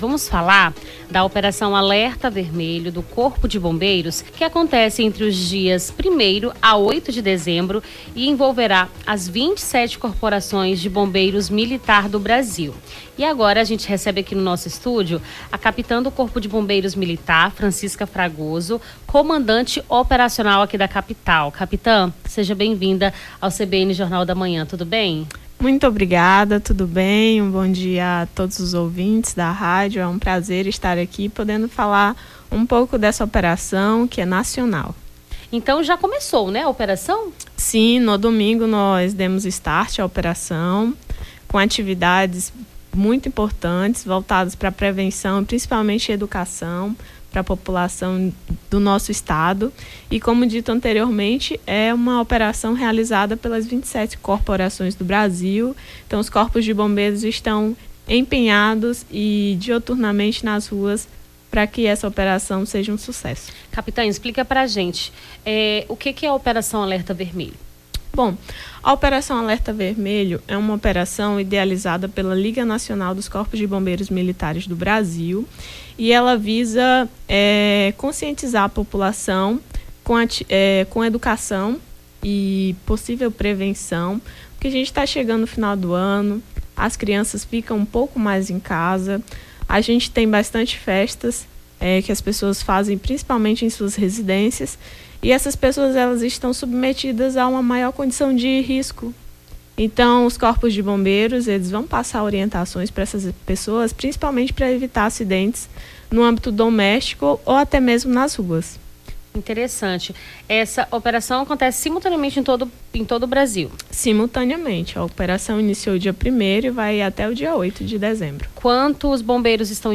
Nome do Artista - CENSURA - ENTREVISTA OPERAÇÃO ALERTA VERMELHO (03-12-24).mp3